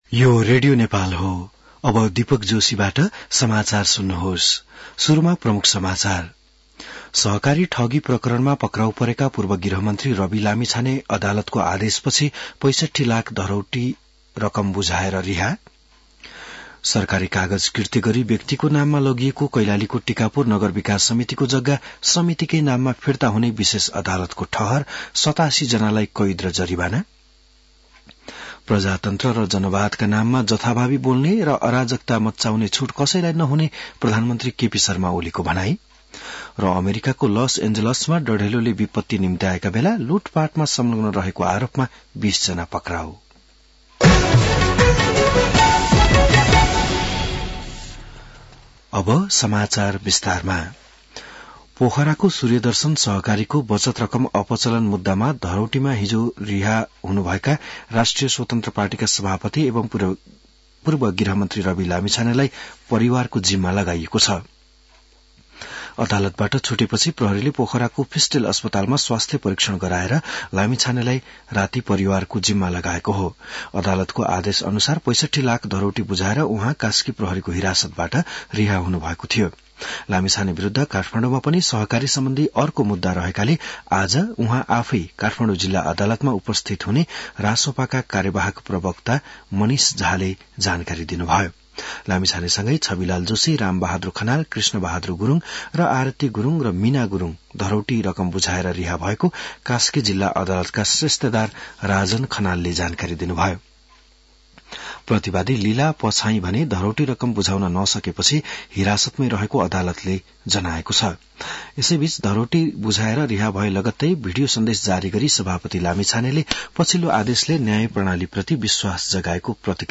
An online outlet of Nepal's national radio broadcaster
बिहान ९ बजेको नेपाली समाचार : २७ पुष , २०८१